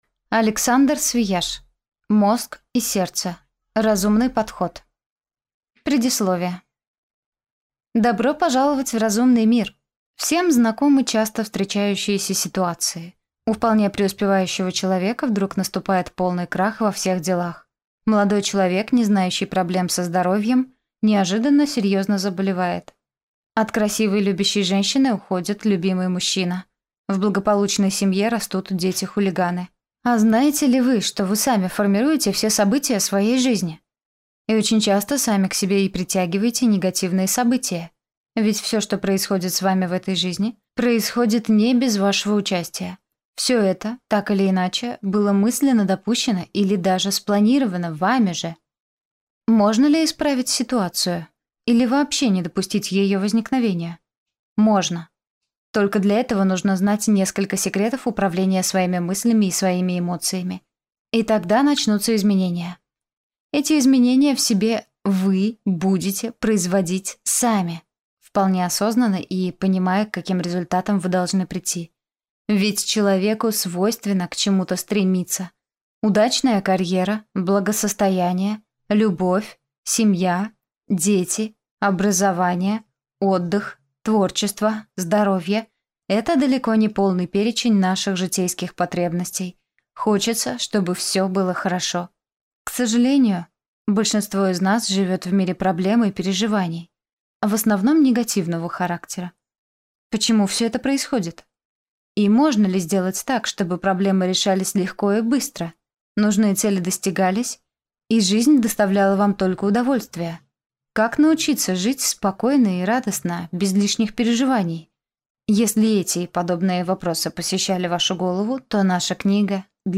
Аудиокнига Мозг и сердце. Разумный подход | Библиотека аудиокниг